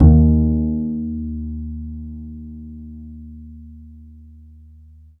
DBL BASS FN2.wav